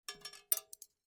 На этой странице собраны звуки вязания: мягкое постукивание спиц, шелест пряжи, ритмичные движения рук.
Шум прядения нитей